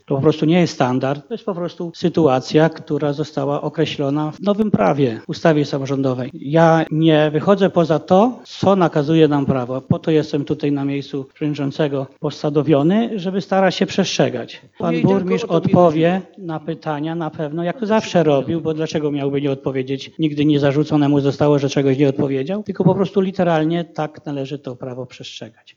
Na pytanie, czy nieudzielanie odpowiedzi na pytania radnych ma być standardem, przewodniczący Rady Miejskiej Krzysztof Wilk (na zdjęciu) odpowiada: